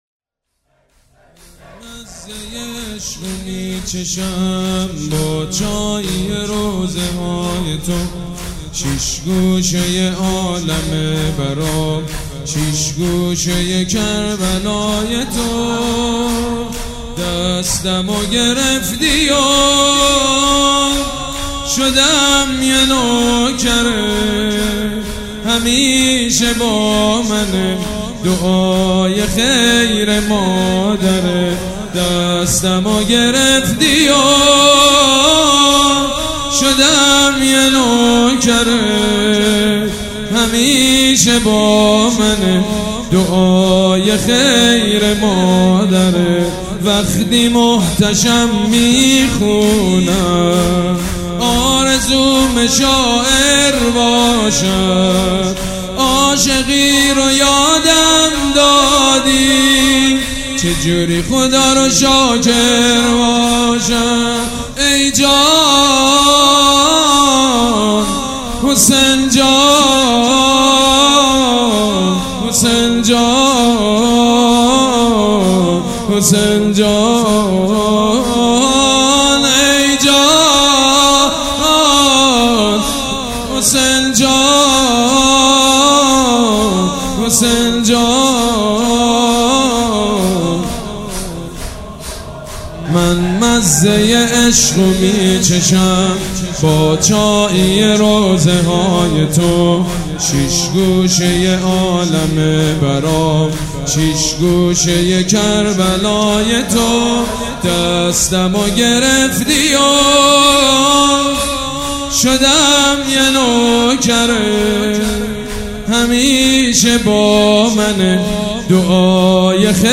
مداحی و نوحه
دانلود banifatemeh-shab5safar139804.mp3 MP3 ۳ MB